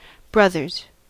Ääntäminen
Synonyymit brethren Ääntäminen US UK : IPA : /ˈbrʌðə(ɹ)z/ Tuntematon aksentti: IPA : /ˈbɹʌð.ɚz/ IPA : /ˈbɹʌð.əz/ Lyhenteet ja supistumat (laki) Bros.